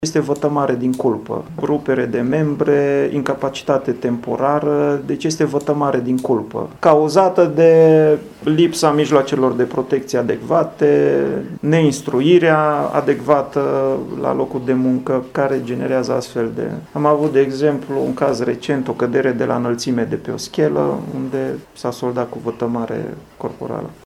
Inspectorul şef al ITM Mureş, Andrei Mureşan.